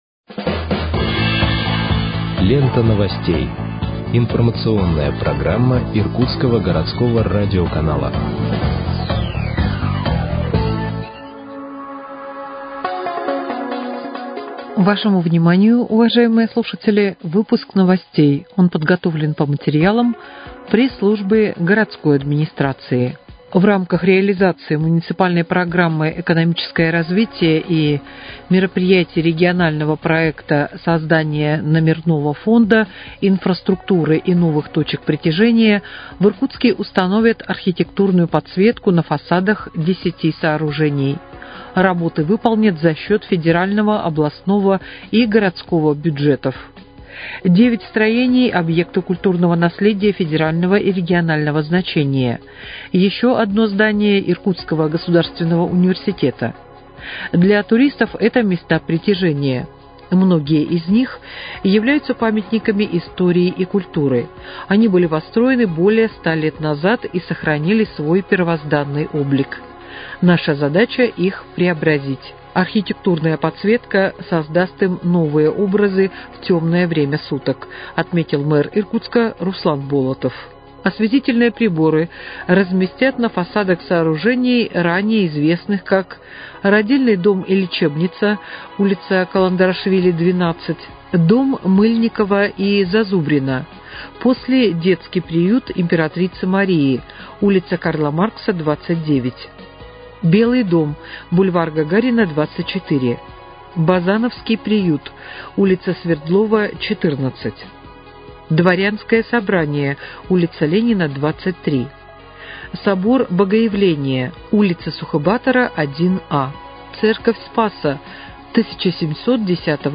Выпуск новостей в подкастах газеты «Иркутск» от 5.06.2025 № 2